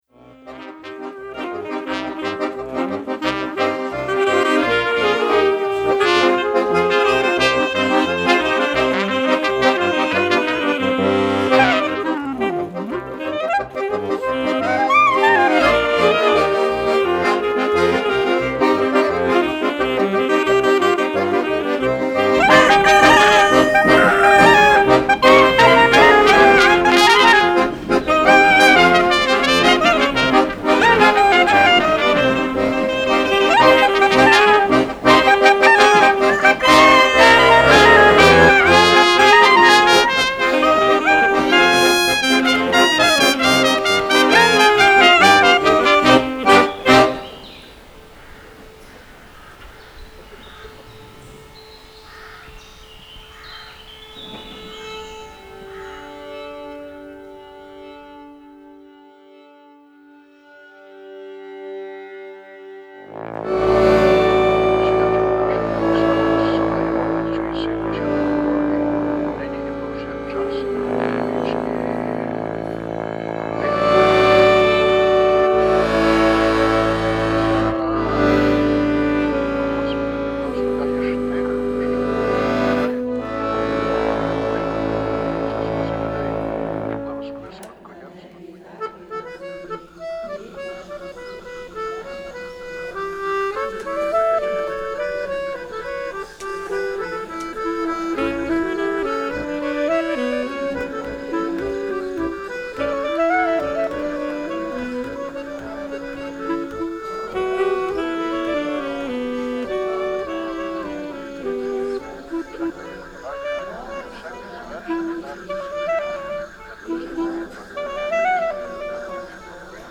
Alle Lieder/Titeln sind überliefertes Volksliedgut ausser:
the electric-acoustic composition